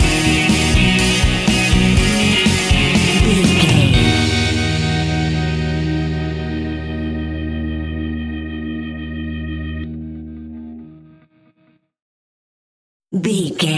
Ionian/Major
E♭
pop rock
indie pop
energetic
uplifting
electric guitar
Distorted Guitar
Rock Bass
Rock Drums
hammond organ